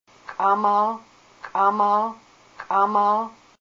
a native speaker of the Kasaan dialect of Alaskan Haida.